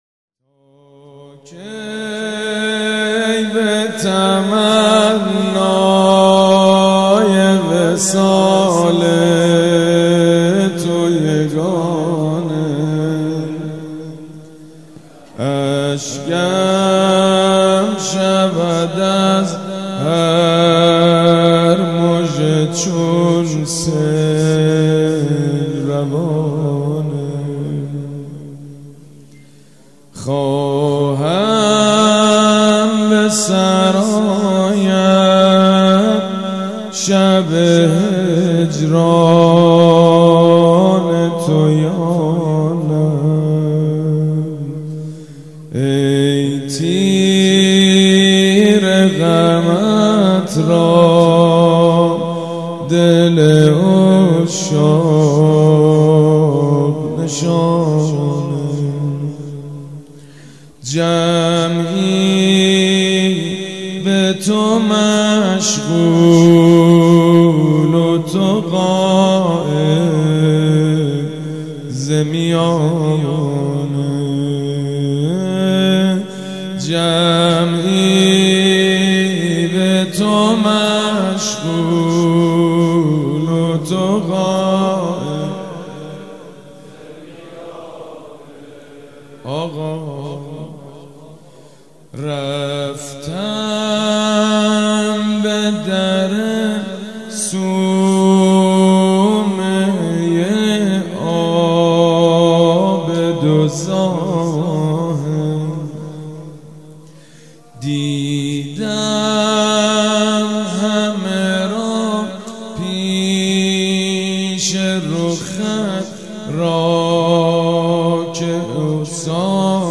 جشن میلاد امام زمان(عج)
شعر خوانی
مداح
حاج سید مجید بنی فاطمه